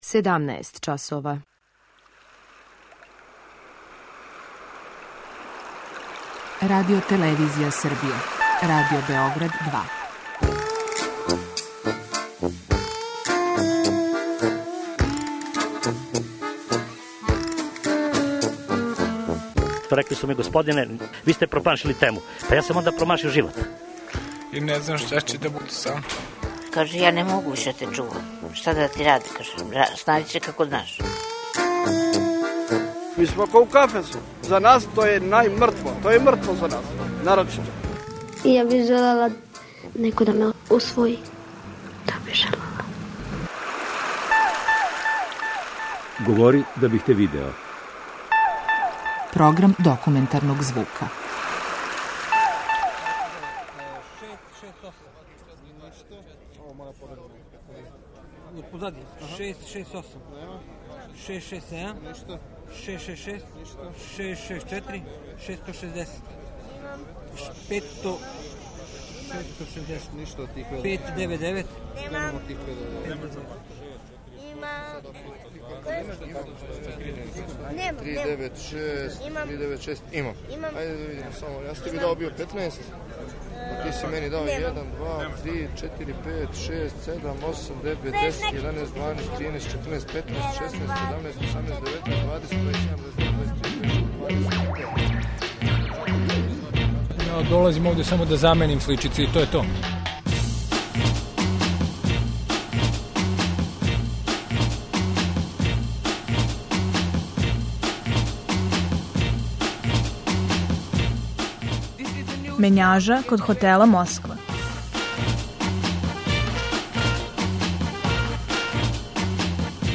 Документарни програм: Мењажа код хотела Москва
Чућете репортажу: „Мењажа код хотела Москва".